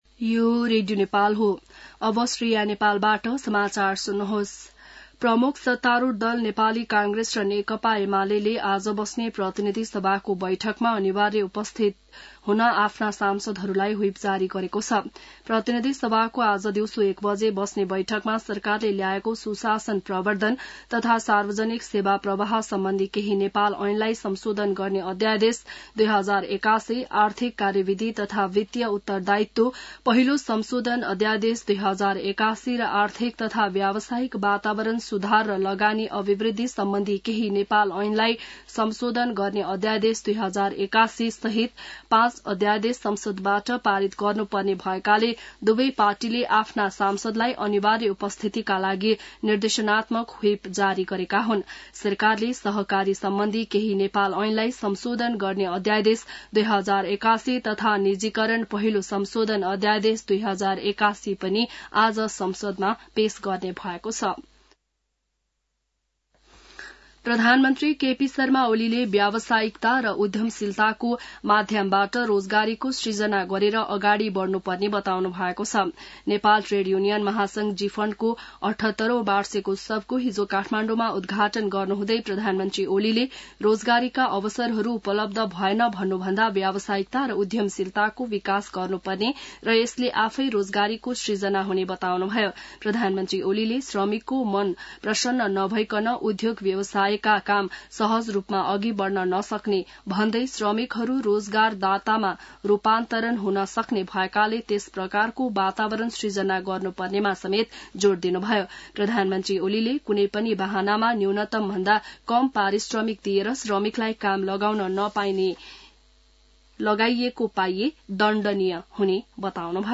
बिहान ६ बजेको नेपाली समाचार : २२ फागुन , २०८१